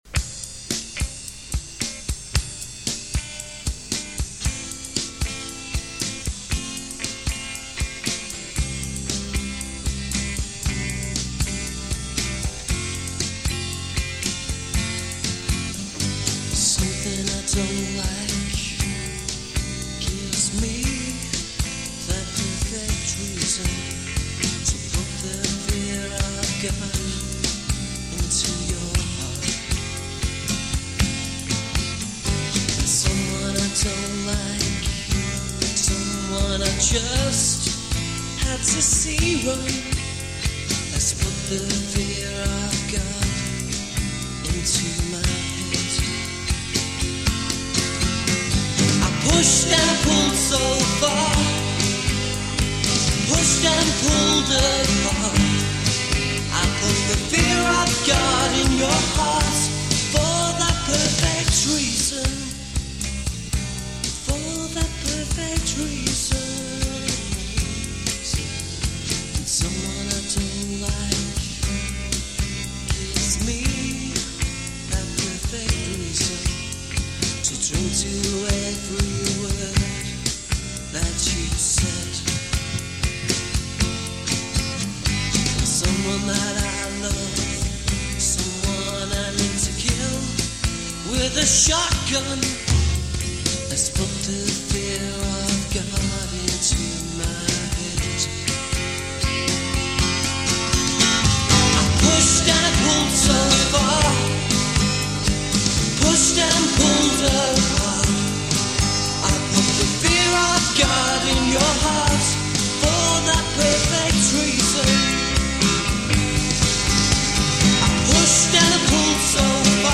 A trio it seems.